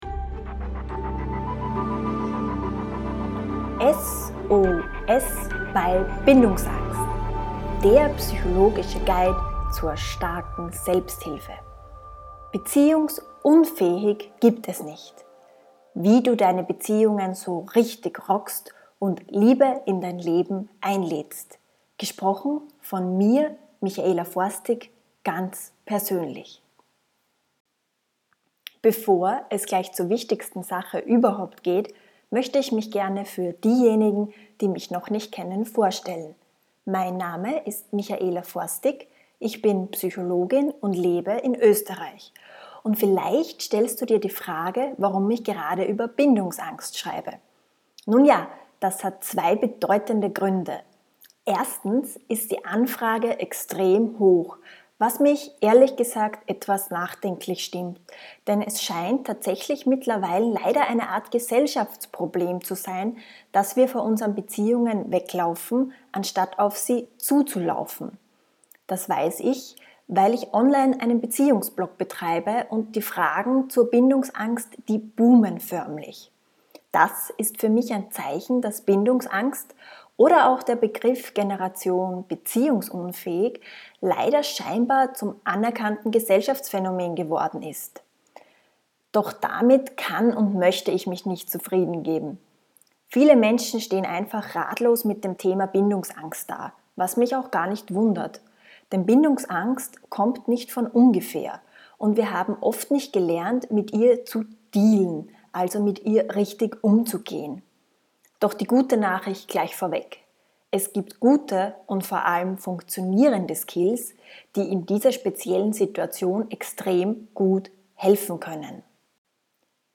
Das Hörbuch zur starken Selbsthilfe
Ein live-Auszug